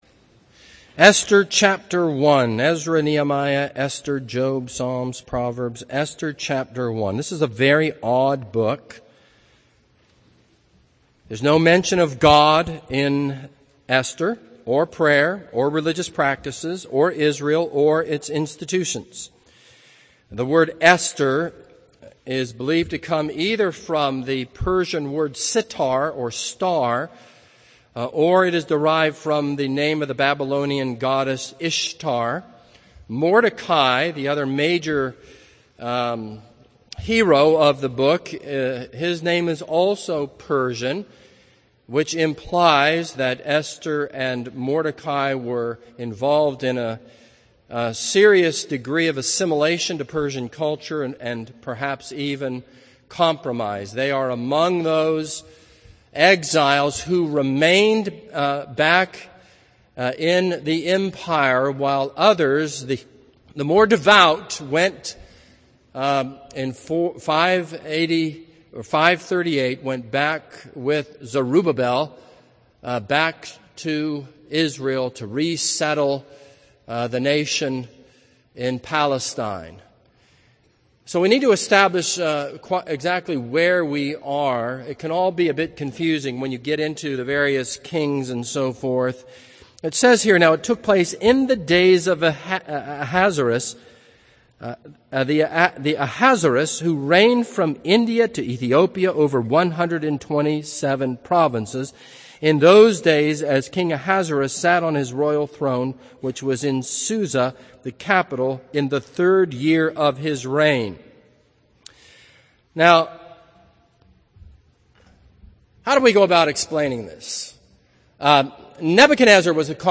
This is a sermon on Esther 1.